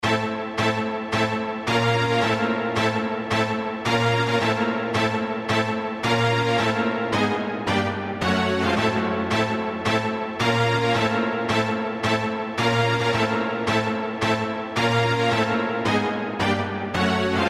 日常声音 " 厕所冲水
描述：冲厕所的声音
声道立体声